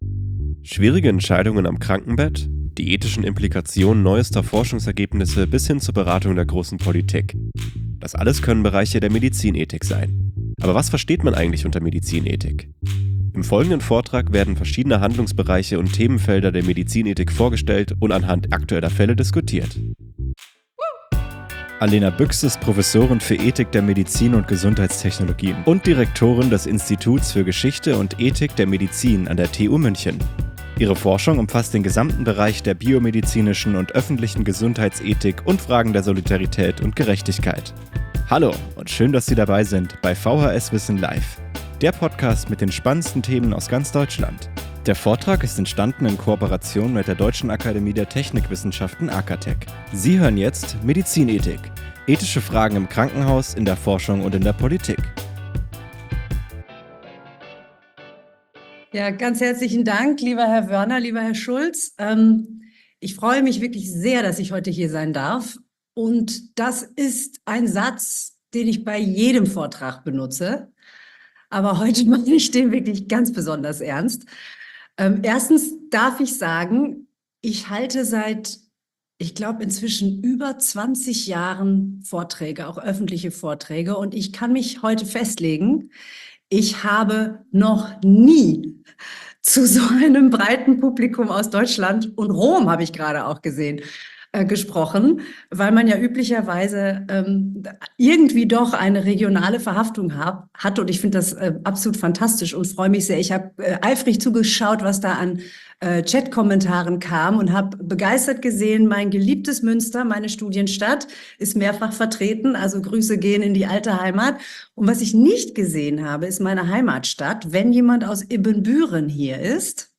Hochkarätige Vorträge von Experten aus Wissenschaft und Gesellschaft digital verfolgen und anschließend live mit Ihnen diskutieren - das bietet vhs.wissen live!